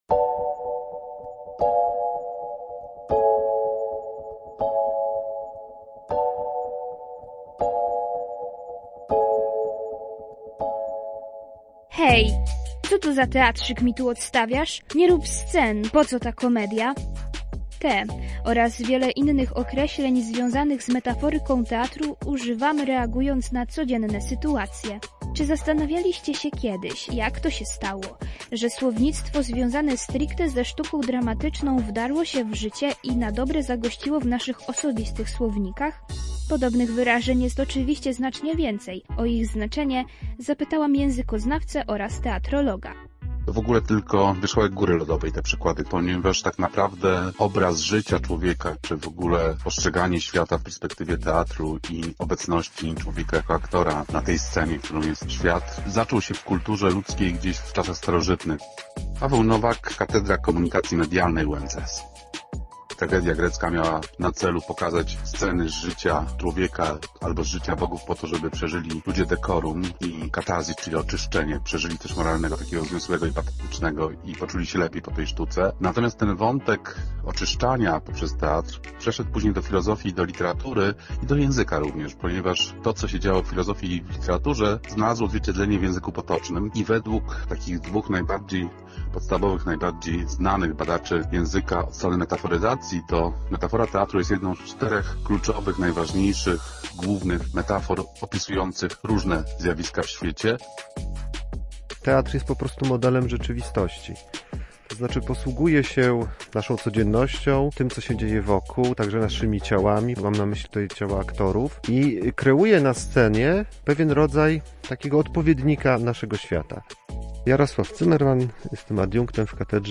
Posłuchajcie materiału naszej reporterki